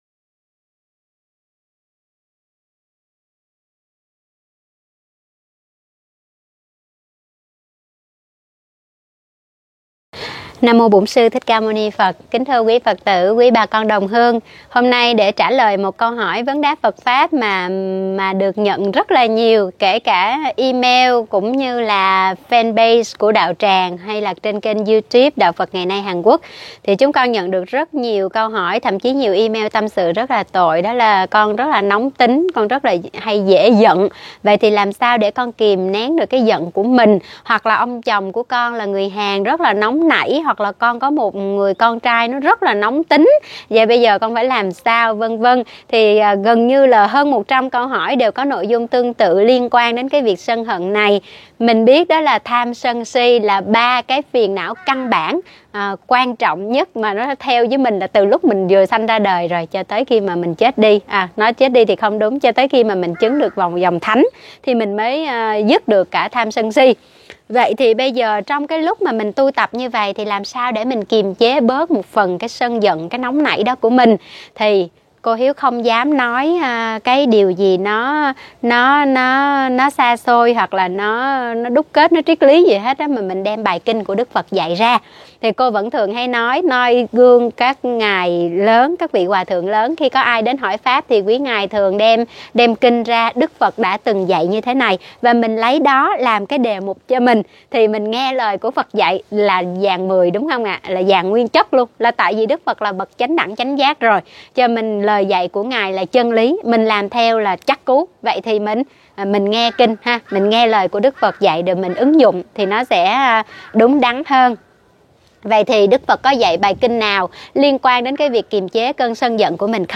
Vấn đáp: Chế ngự tâm sân qua kinh Diệt trừ phiền giận (kinh Thủy dụ)